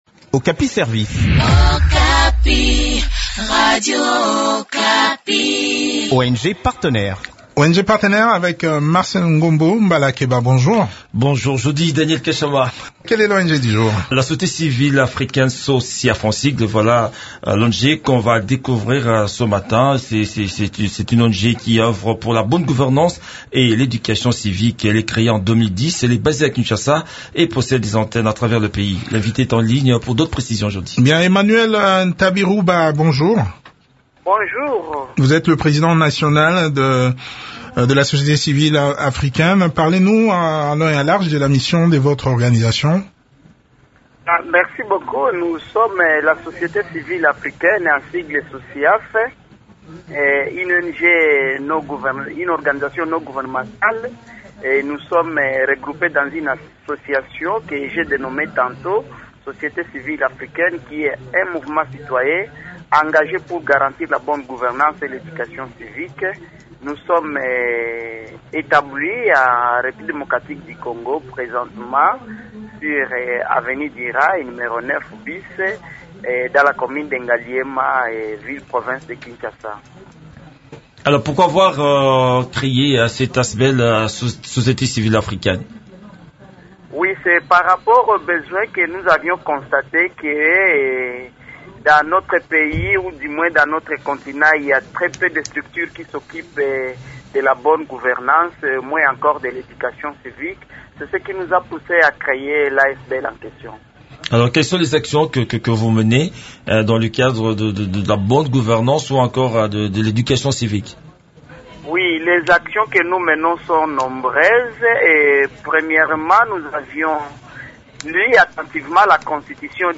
Le point des activités de cette ONG dans cet entretien